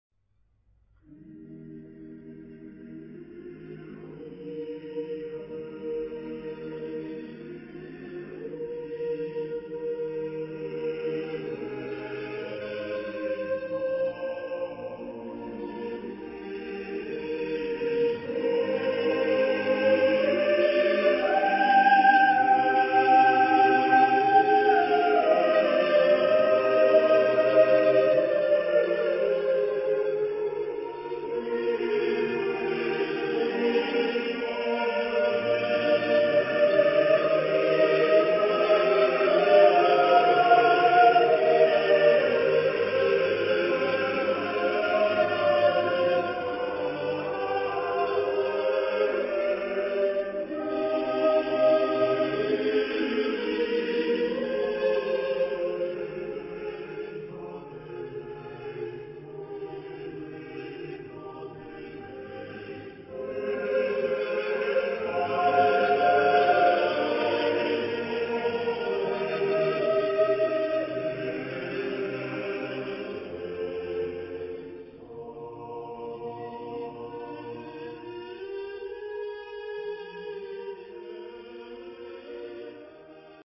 Género/Estilo/Forma: Romántico ; Sagrado ; Misa
Tipo de formación coral: SATB + SATB  (8 voces Doble coro O Coro mixto )
Tonalidad : mi bemol mayor
por Kammerchor Stuttgart dirigido por Frieder Bernius